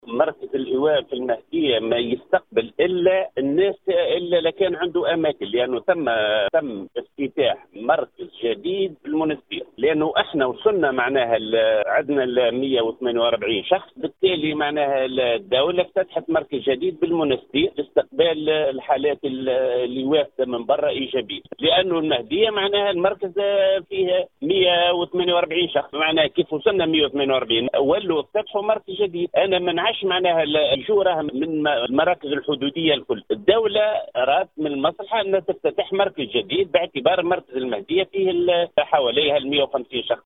اكد المدير الجهوي للصحة بالمهدية عمر بن منصور في تصريح خص به ام اف ام ، انه قد بلغ عدد حالات ايواء حاملي فيروس كورونا أقصاه بجهة المهدية بما يقارب 150 شخصا ما استوجب تخصيص مركز جديد بولاية المنستير لاستقبال الحالات الوافدة و الحاملة للفيروس.